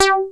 RM12BASS G4.wav